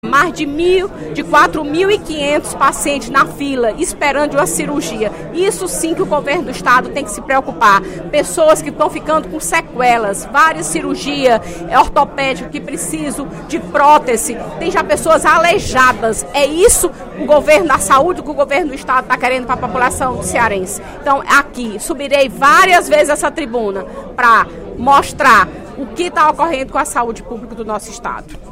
No primeiro expediente da sessão plenária desta terça-feira (1°/04), a deputada Fernanda Pessoa (PR) criticou o desabamento do teto da sala de emergência do Hospital Geral de Fortaleza (HGF).